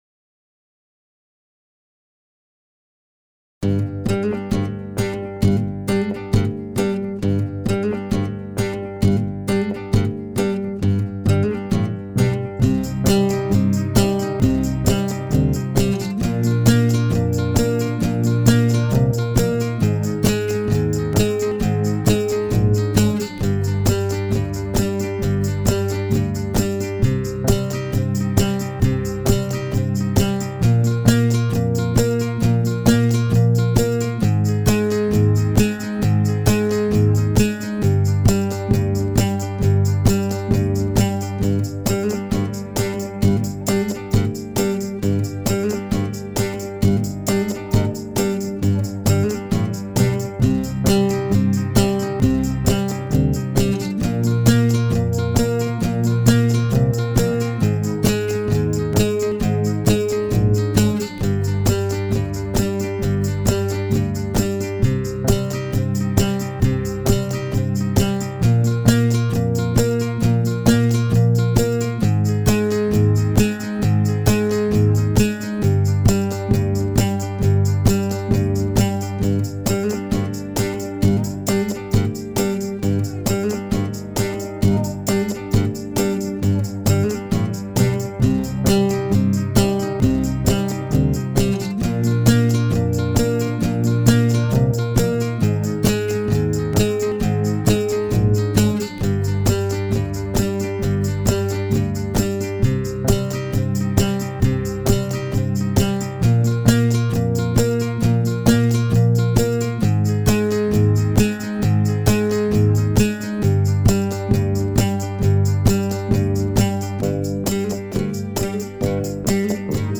Solo de guitare por ZAMBRA #3
La Zambra vient des Maures, les mélodies sont arabisé.
La Zambra s'est niché du côté de Granada dans les grottes del Sacromonte elle était joué dans les rituels de mariages Gitans, ce qui lui donne une sonorité arabo-andalouse.